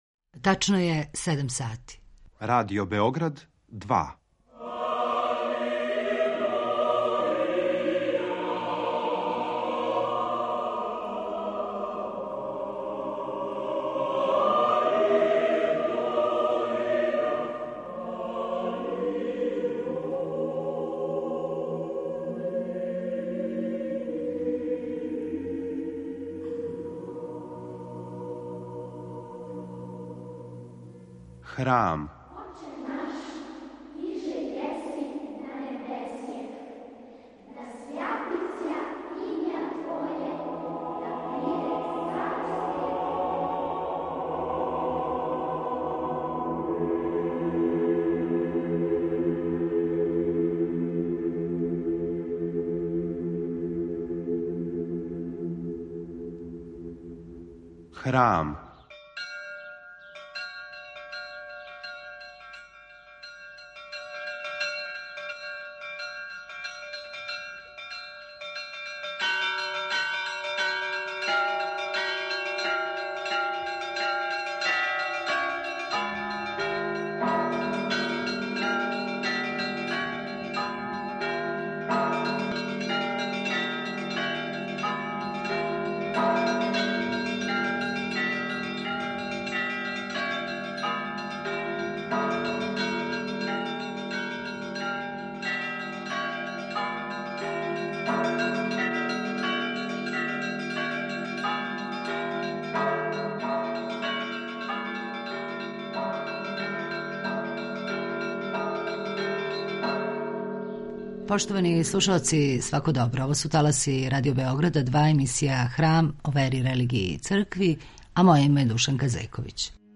Гост је историчар и публициста